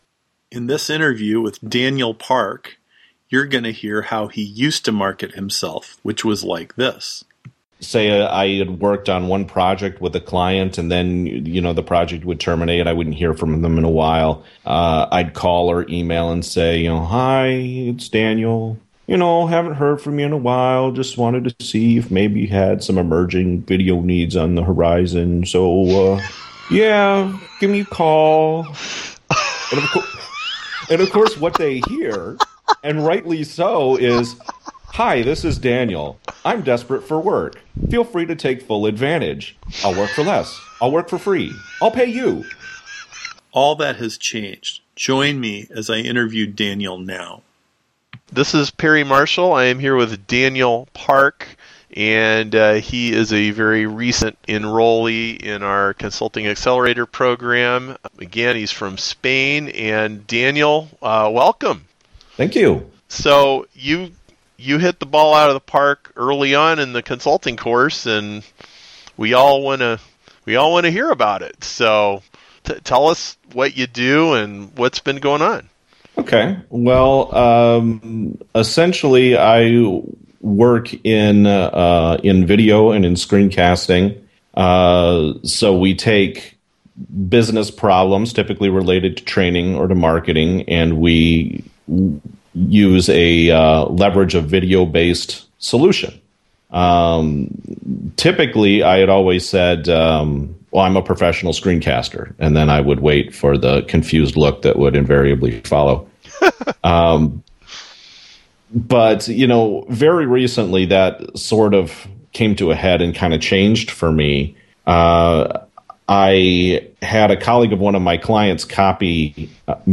This interview opens with a HILARIOUS story.